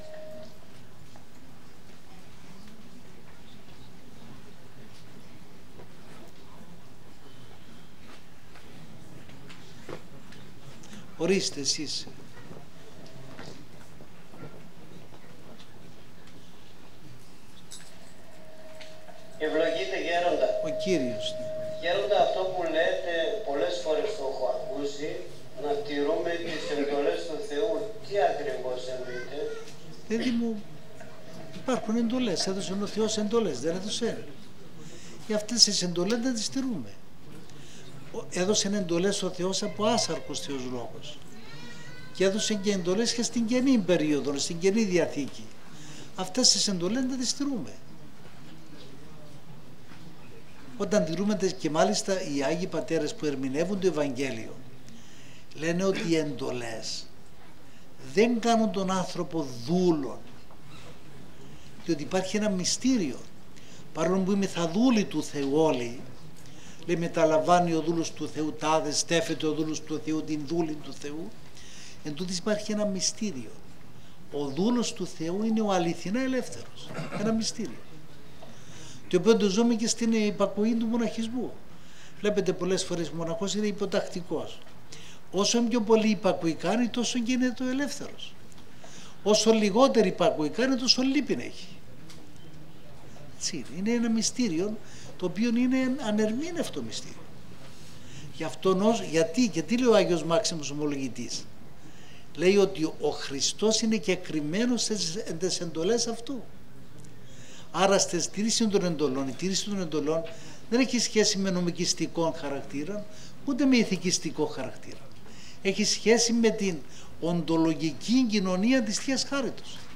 συζήτηση
Μετά το πέρας της ομιλίας οι παρευρισκόμενοι ακροατές έκαναν ερωτήσεις και κατέθεταν τους προβληματισμούς τους
ερωτήσεις.m4a